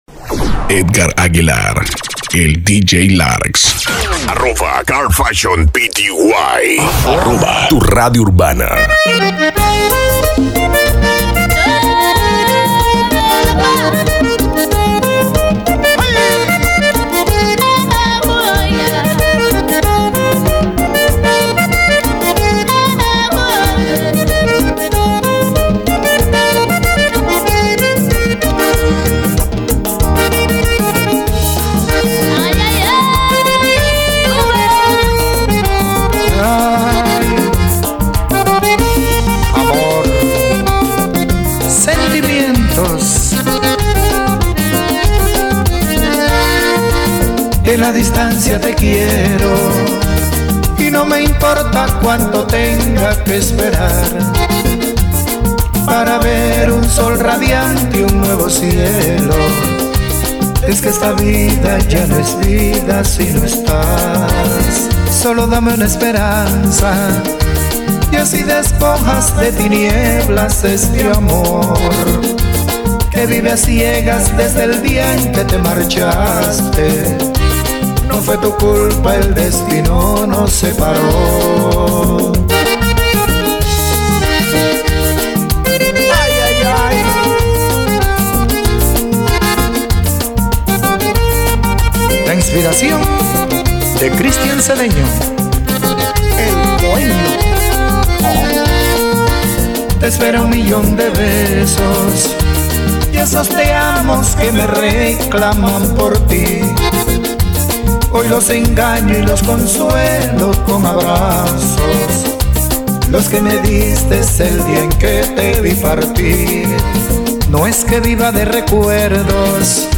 mix exclusivo